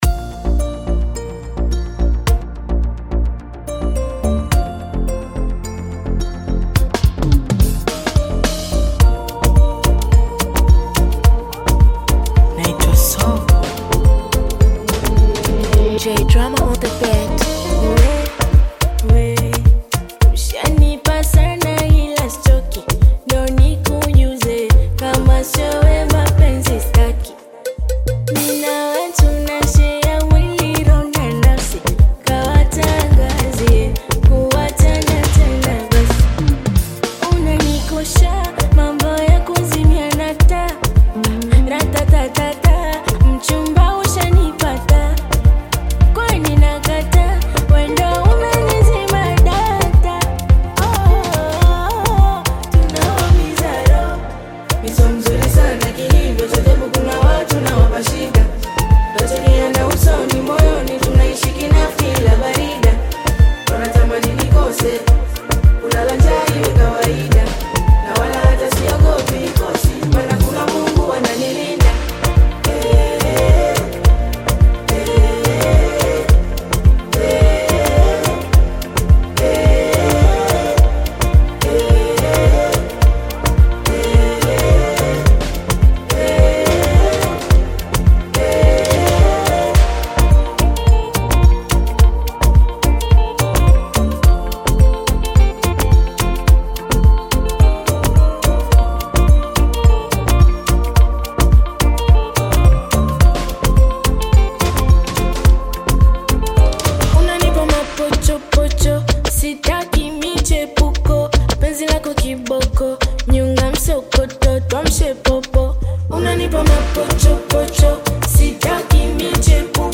Tanzanian Bongo Flava artist
Bongo Flava You may also like